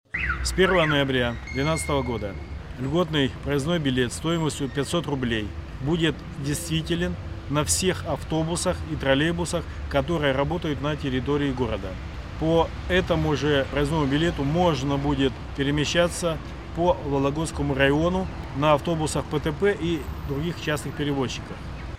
Евгений Шулепов рассказывает о действии проездного «Забота»